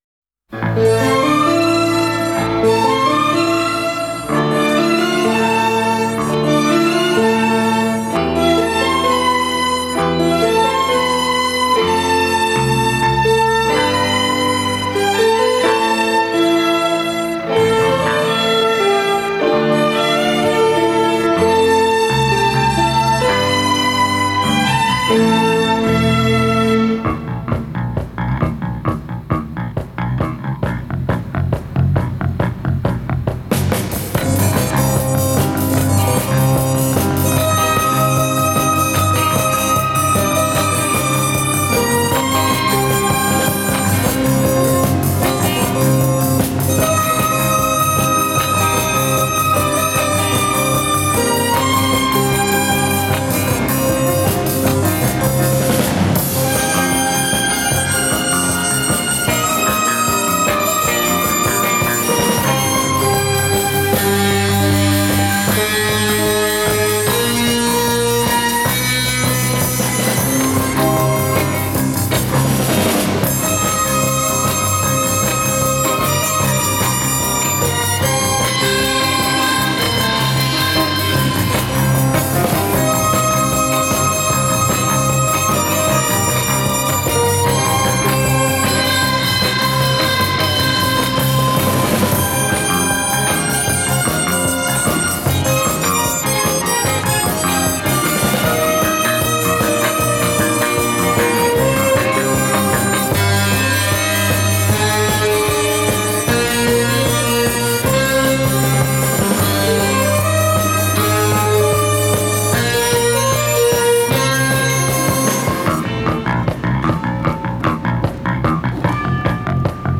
спасибо-но это всё в моно
видимо нет -записали на студии в моно